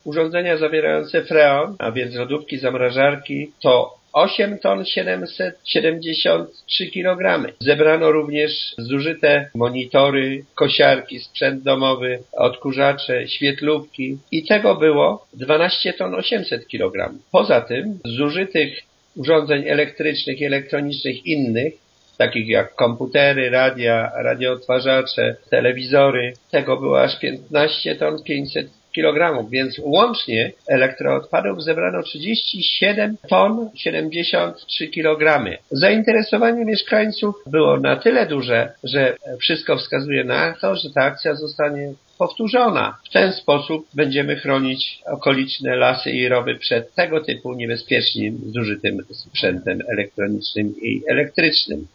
„W połowie września, tylko w ciągu trzech dni, udało się zebrać ponad 37 ton elektrośmieci” – podsumowuje zastępca burmistrza Włodzimierz Kabus: